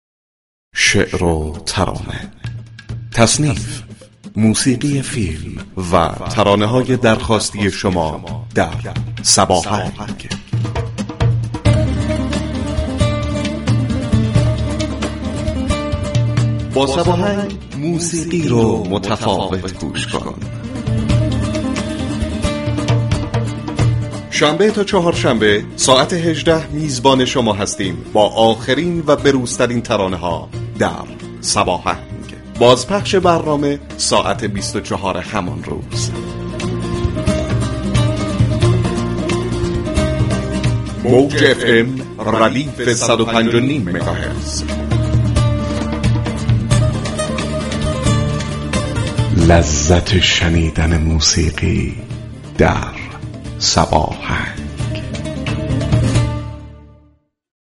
رادیو صبا در برنامه موسیقی محور صباهنگ با شاهین فرهت پدر سمفنونی ایران گفتگو صمیمی داشت.
به گزارش روابط عمومی رادیو صبا، شاهین فرهت آهنگساز، موسیقی‌دان و مدرس موسیقی خوب كشورمان مهمان تلفنی برنامه صباهنگ رادیو صبا شد.